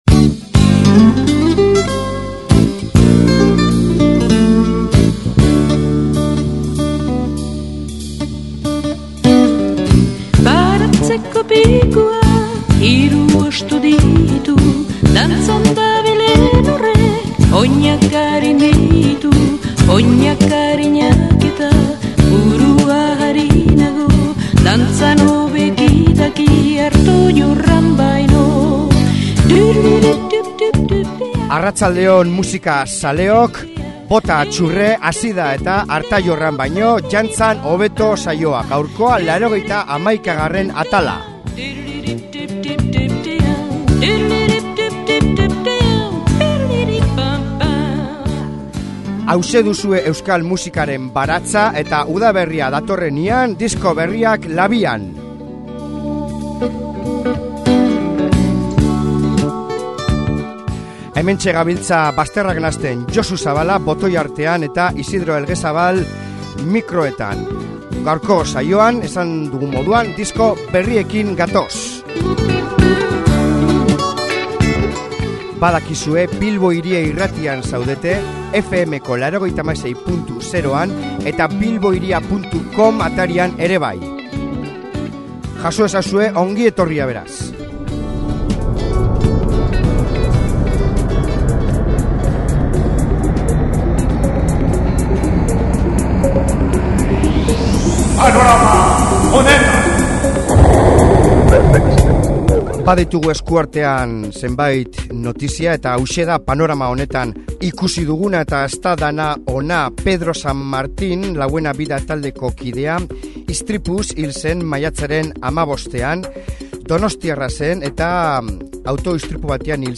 ska-punk parranderoa
rock gogorra